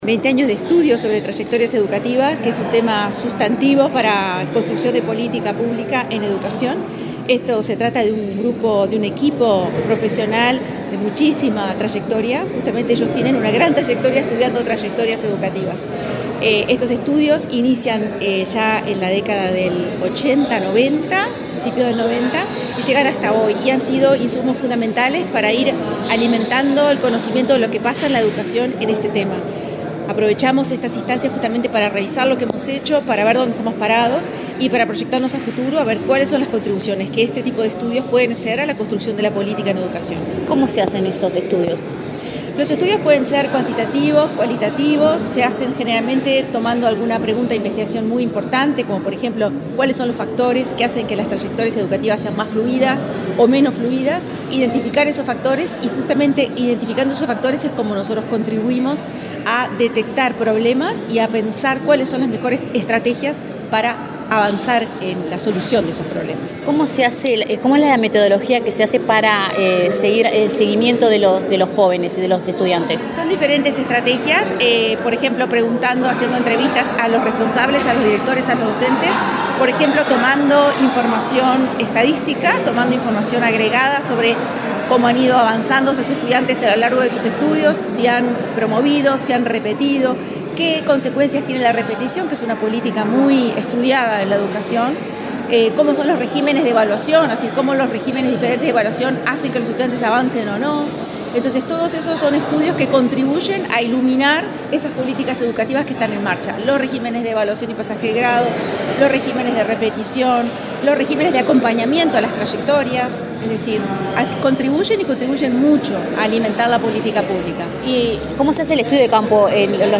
Declaraciones de la directora ejecutiva de Políticas Educativas de la ANEP
La Administración Nacional de Educación Pública (ANEP), a través de la Dirección Sectorial de Planificación Educativa, realizó este miércoles 16 el seminario “20 años de trayectorias educativas en la ANEP”. La directora ejecutiva de Políticas Educativas, Adriana Aristimuño, dialogó con Comunicación Presidencial sobre la temática.